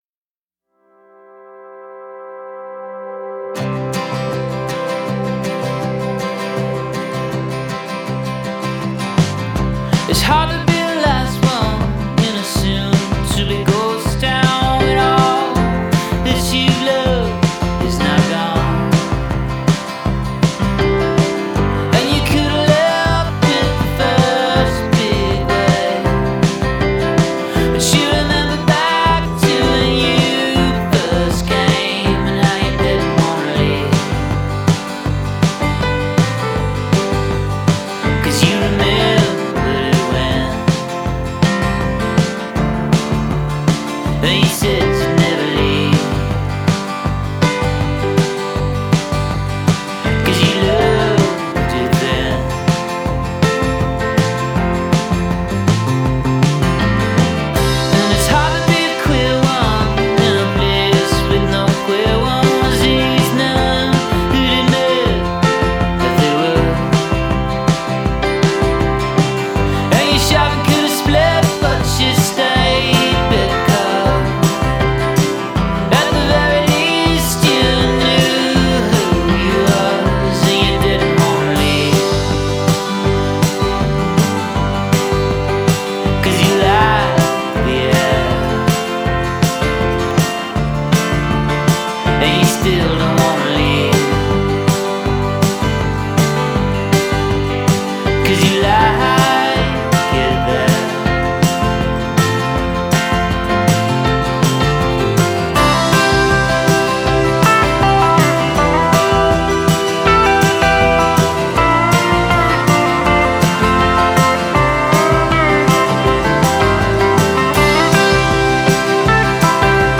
showcasing a full band sound.